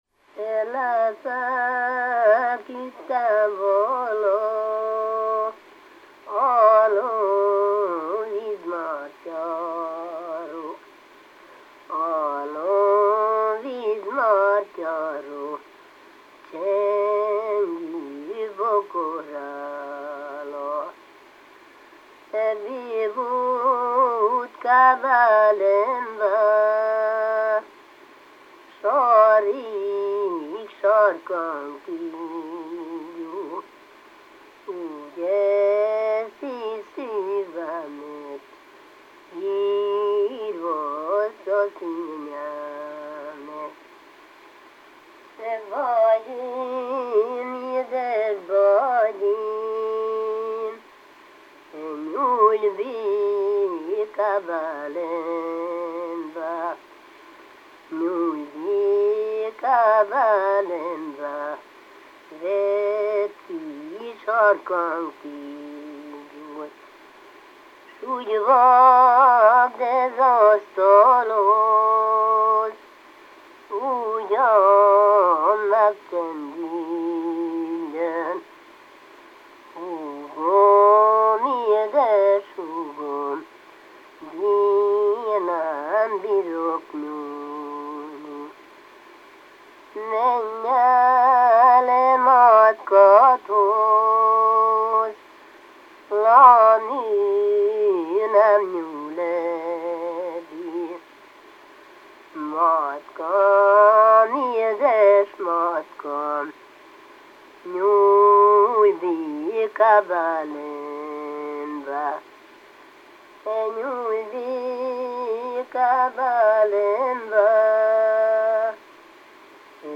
ének
ballada
Klézse
Moldva (Moldva és Bukovina)